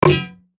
Golf_Hit_Barrier_2.ogg